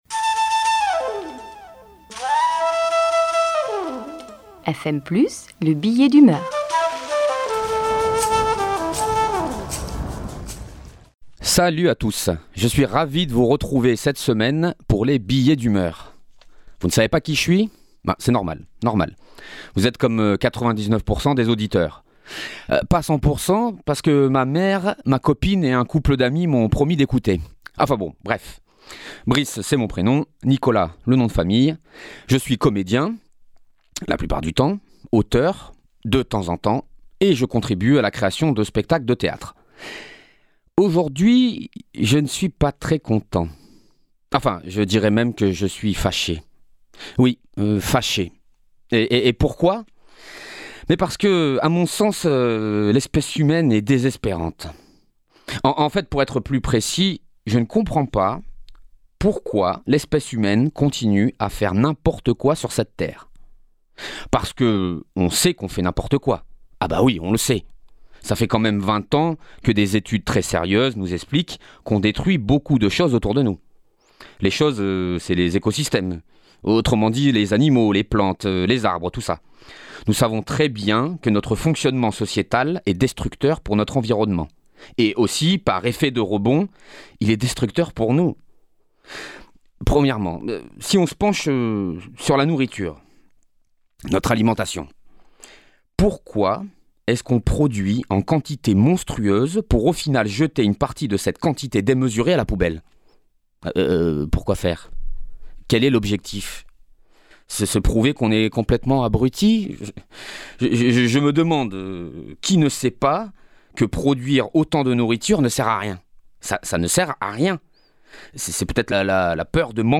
Billet d’humeur